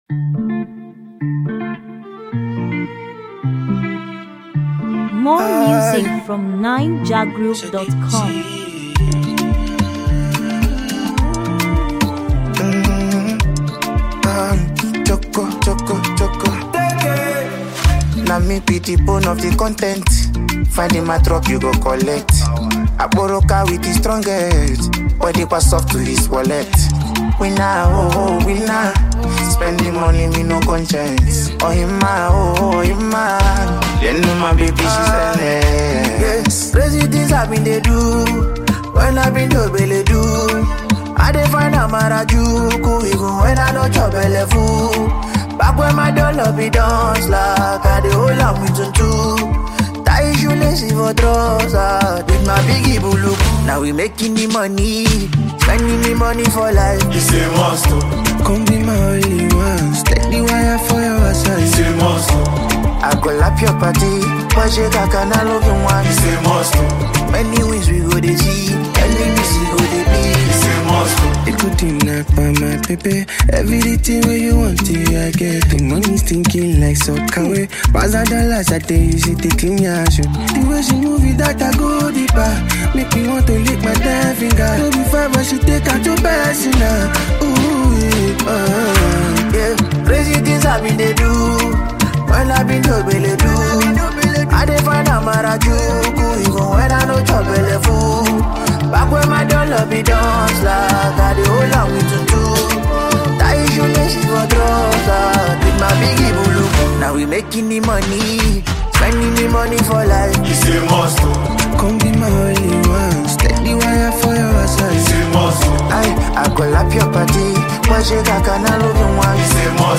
Latest, Naija-music